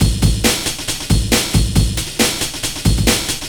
cw_amen12_137.wav